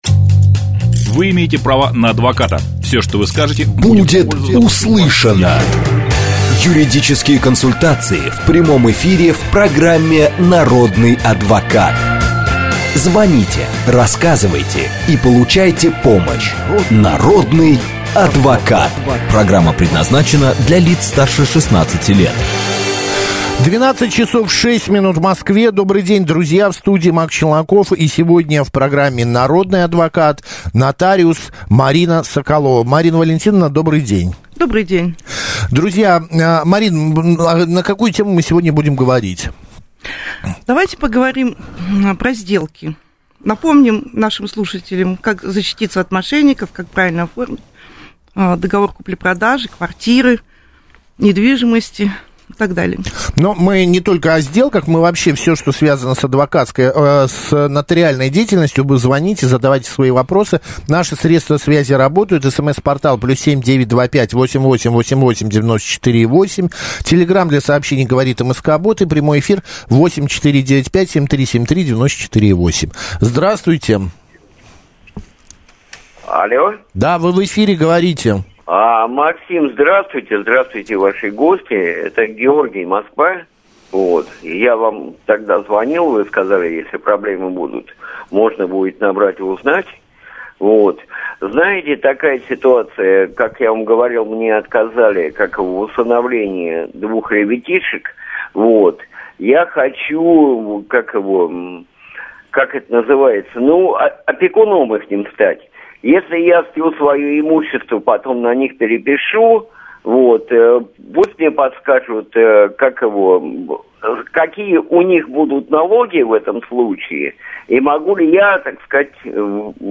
в прямом эфире программы «Народный адвокат» на радио «Говорит Москва»
В программе было много актуальных вопросов слушателей, которые выходили за рамки частных ситуаций. Например, один спросил, как лучше продавать квартиру: через онлайн-сервис банка или через нотариуса? Нотариус сравнила эти варианты по ключевым критериям, и по всем из них предпочтительнее оказалась нотариальная форма.